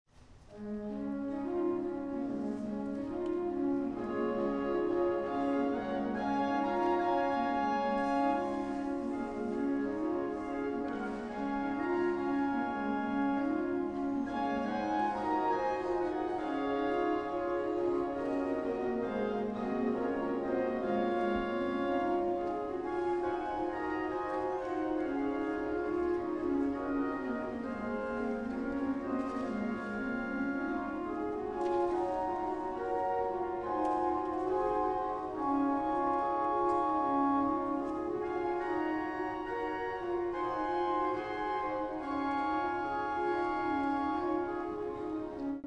Concert sur l'orgue Callinet de l'église Notre-Dame du Marthuret à Riom
Les extraits montrent quelques échantillons des sonorités particulières de l'orgue.